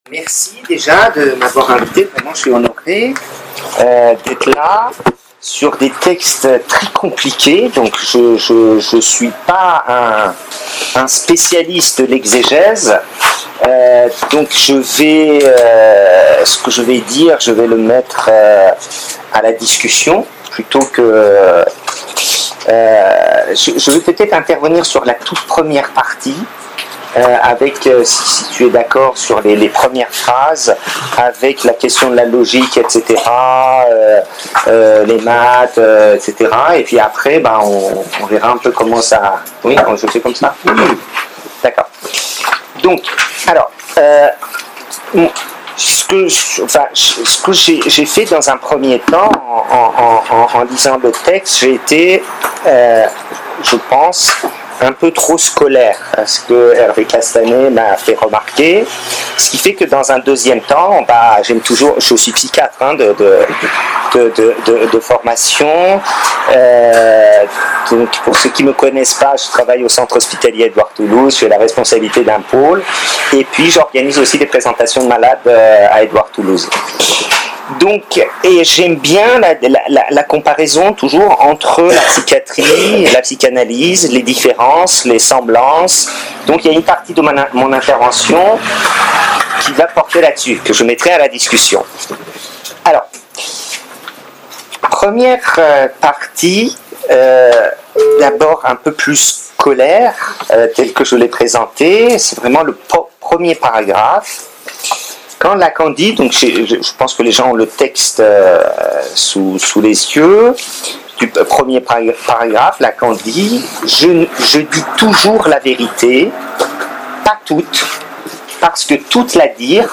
Le Séminaire ACF MAP sur « Télévision » de Jacques Lacan s’est tenu à Marseille sur 10 séances, du 20 septembre 2018 au 20 juin 2019, à la bibliothèque du Pôle Psychiatrique.